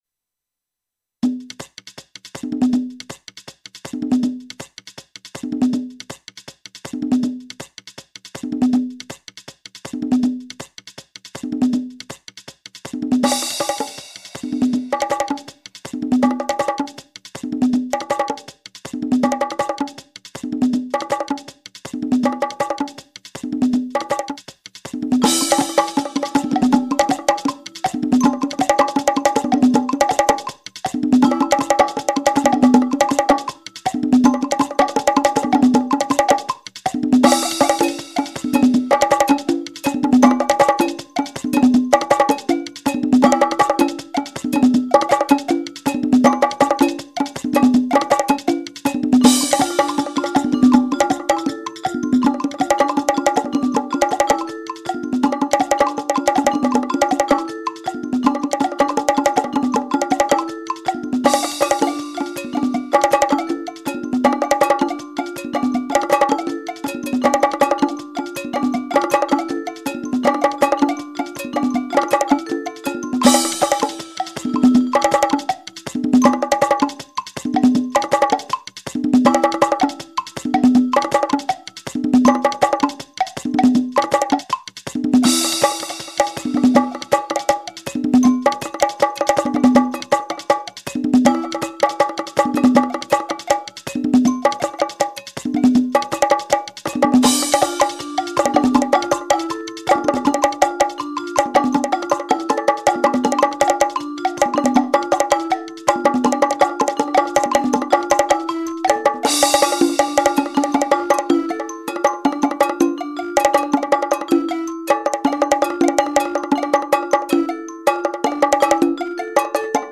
Description Short and fast! Bongos and congas